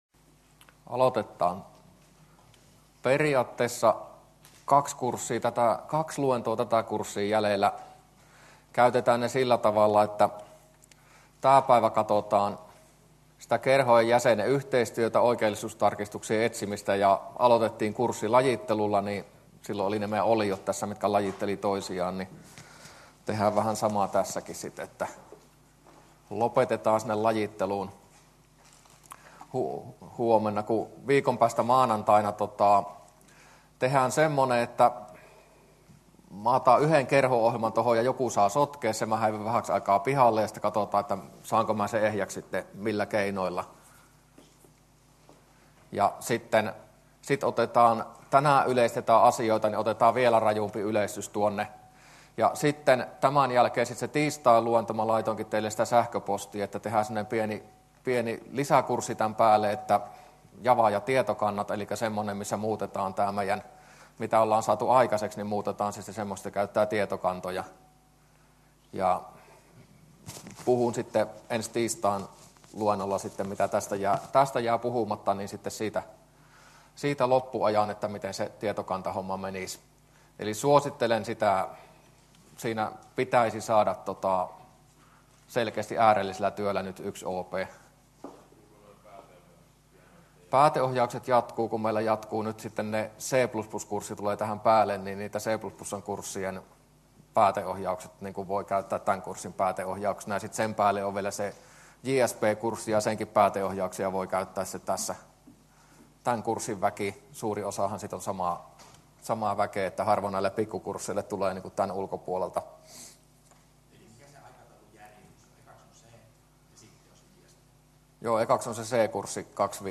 luento23a